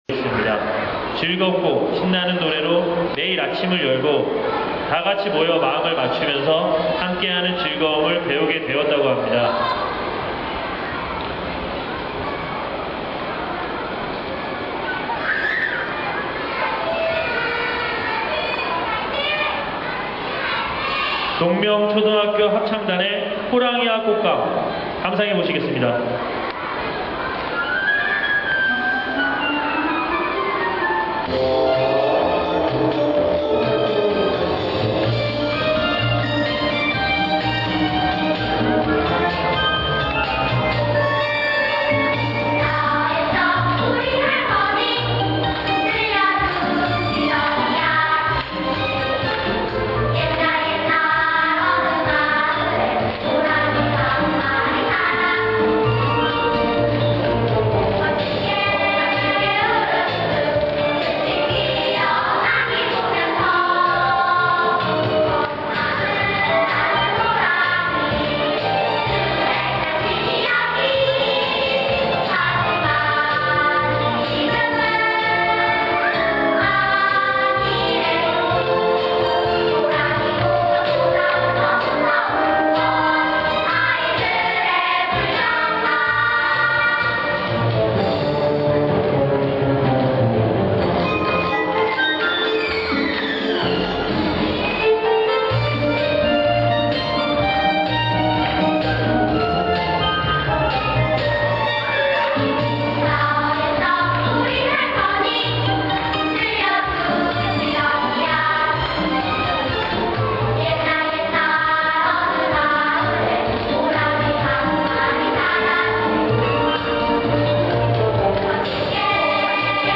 공지사항 - 제13회 강원도 소방동요제 동영상 상세화면 | 강릉소방서 > 알림마당 > 공지사항
유치부 : 강릉우석어린이집 / 초등부 : 강릉동명초등학교